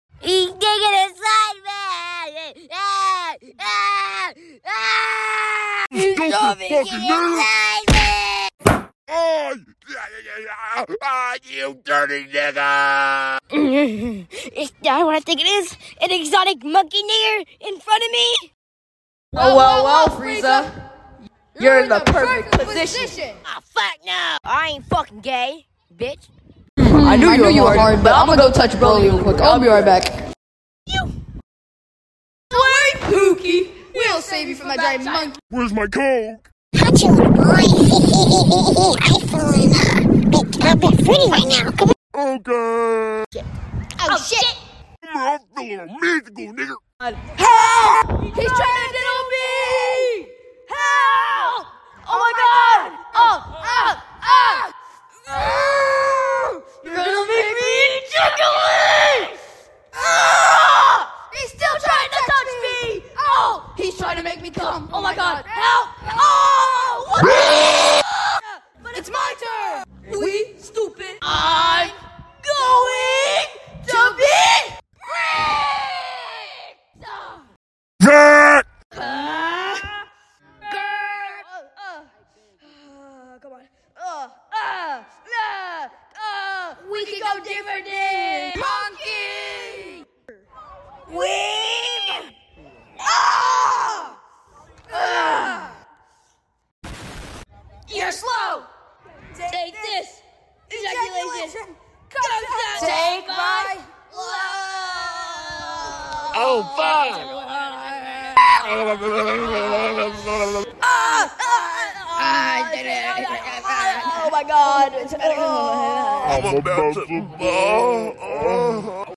A Dragon BallZ voiceover credit sound effects free download